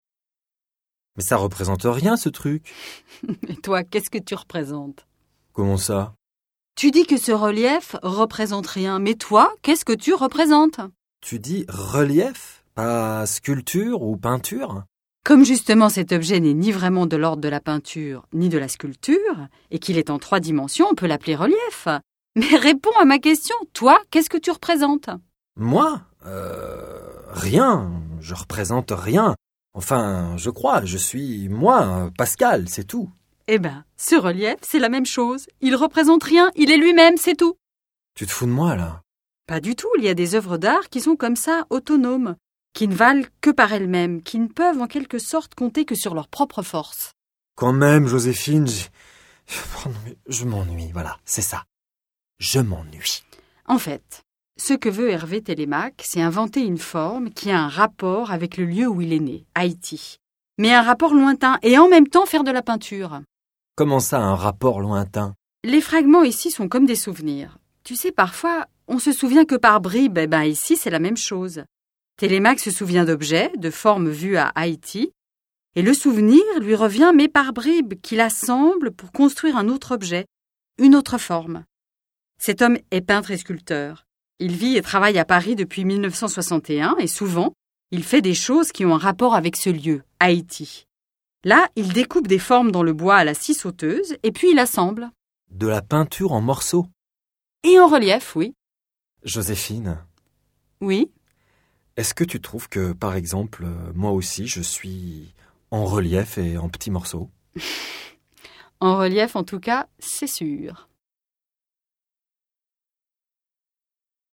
L’audioguide du Parcours #3 est un dialogue fictif entre deux visiteurs.